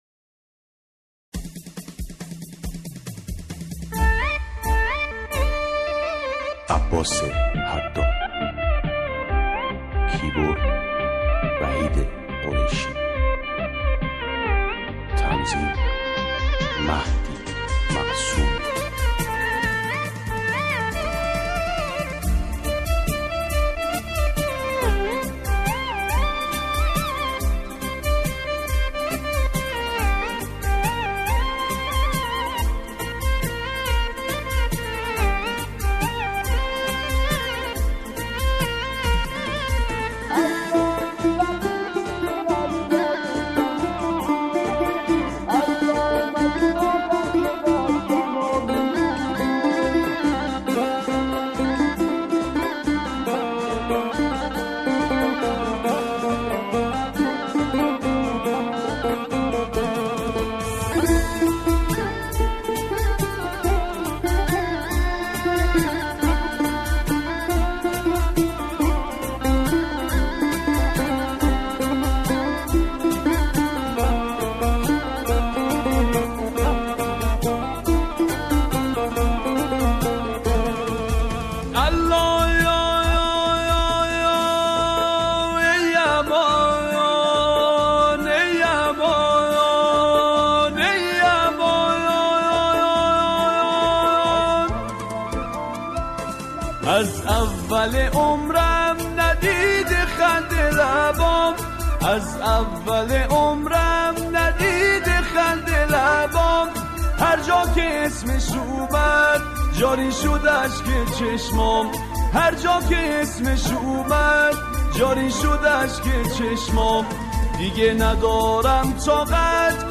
آهنگ بسیار غمگین.